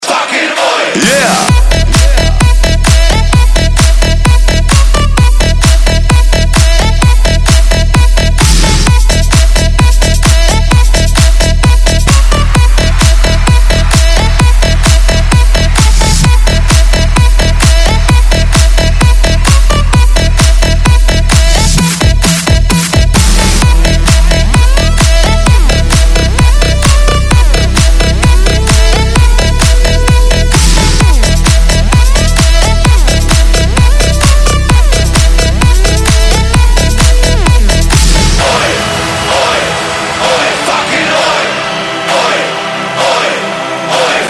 • Качество: 128, Stereo
Техно
техно музыка
рингтон сделан по принципу замкнутого микса.